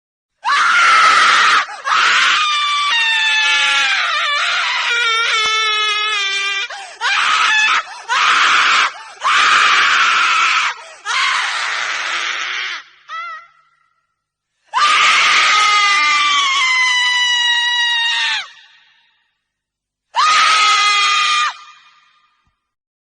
Scary Woman Screaming - Sound Effect (HD)